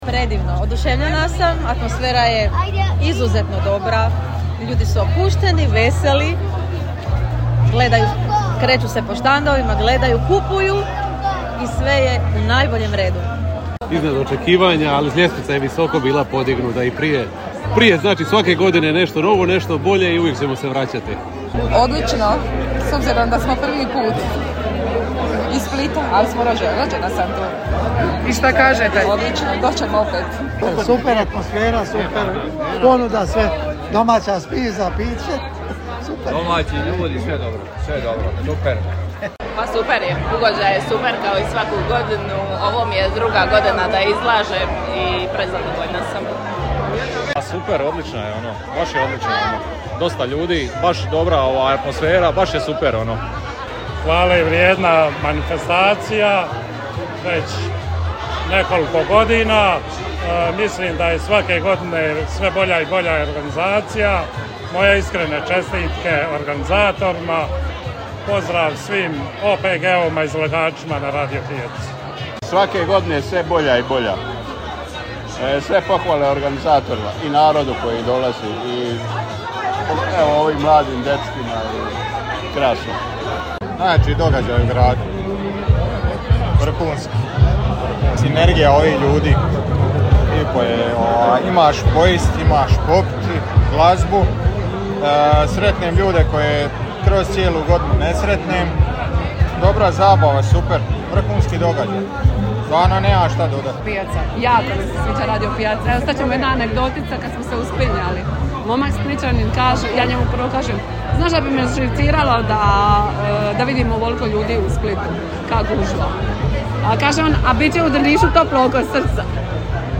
Održana je 12. Radio pijaca Radio Drniša, noćni sajam tradicije i običaja drniškog kraja.
Pijaca-posjetitelji-izjave-.mp3